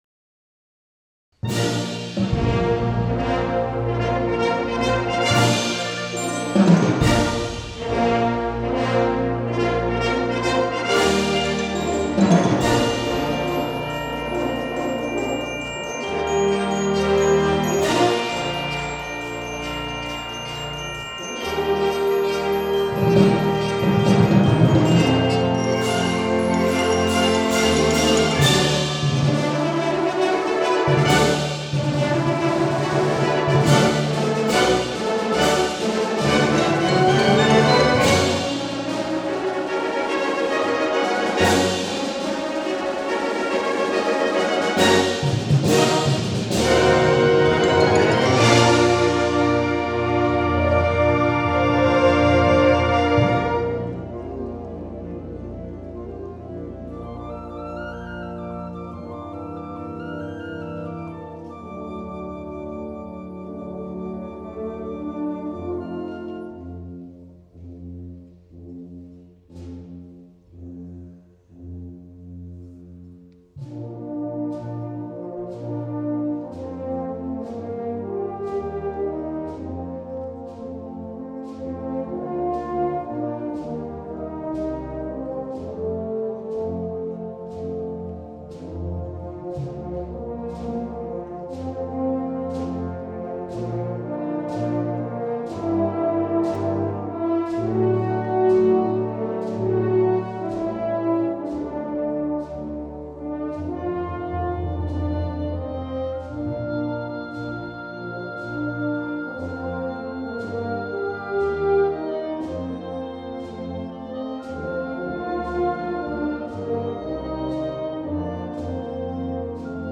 Genre: Band
Ensuing, are three variations utilizing the chorale theme.